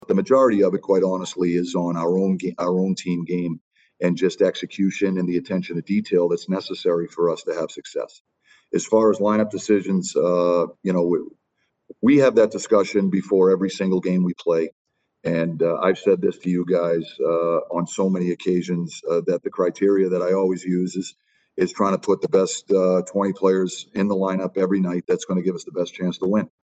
Coach Mike Sullivan says their focus was not so much on the Flyers.